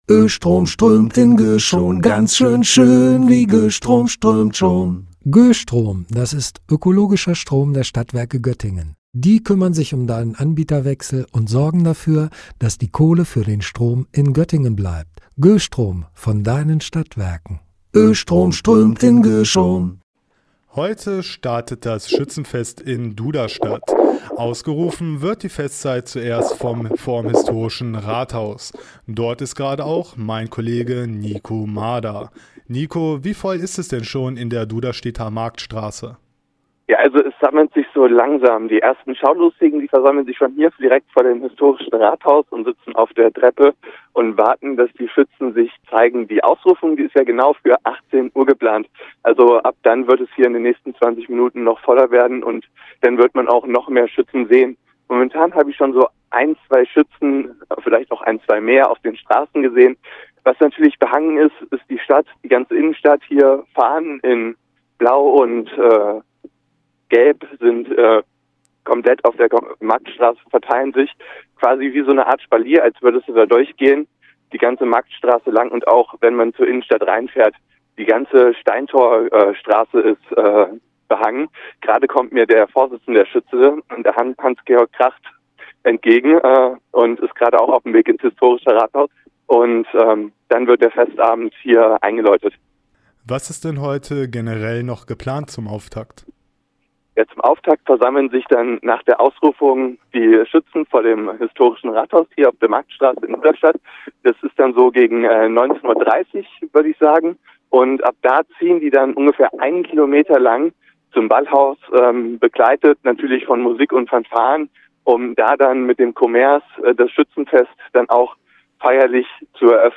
Liveschalte vom Duderstädter Schützenfest